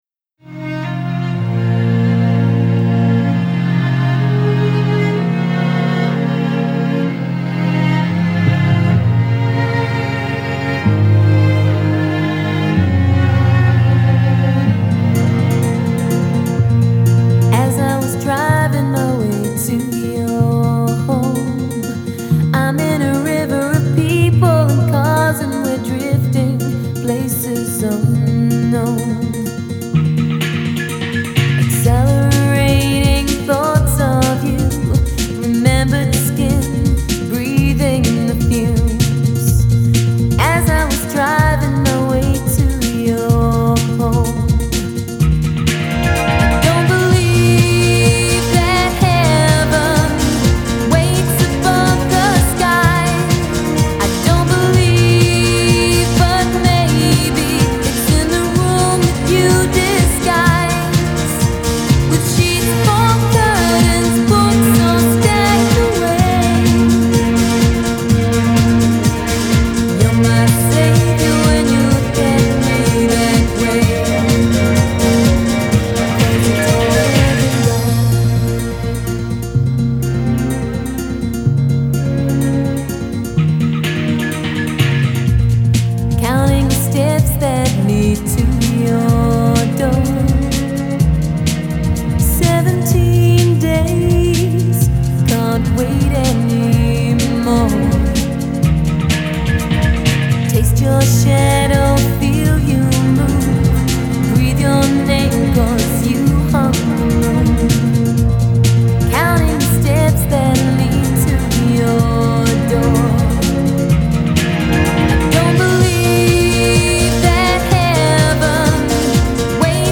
Genre: Hip Hop, Jazz, Rock, Funk